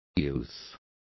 Complete with pronunciation of the translation of youths.